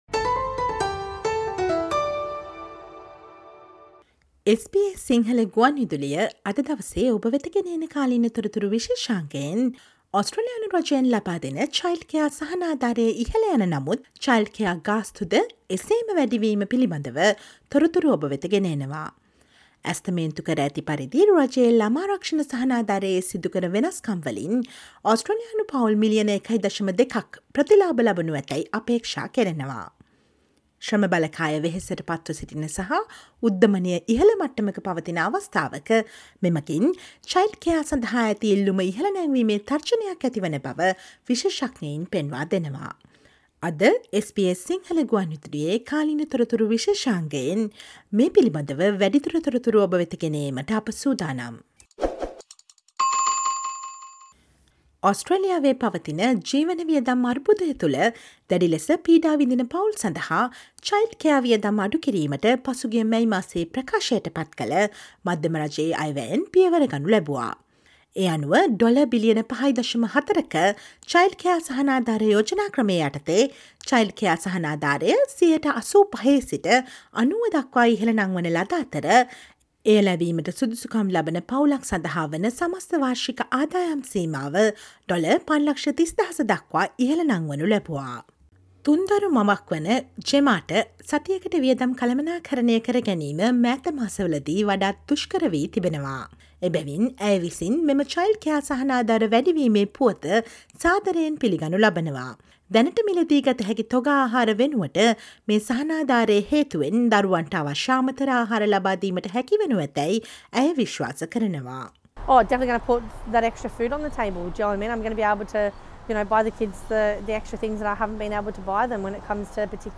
ඔස්ට්‍රේලියානු පවුල් මිලියන 1.2ක් ප්‍රතිලාභ ලැබෙනු ඇතැයි සිතන childcare සහනාධාර වැඩි වීම මෙන්ම childcare ගාස්තු වැඩි වීම පිළිබඳ නවතම තොරතුරු දැන ගන්න සවන් දෙන්න අද SBS සිංහල ගුවන් විදුලි වැඩසටහනේ කාලීන තොරතුරු විශේෂාංගයට.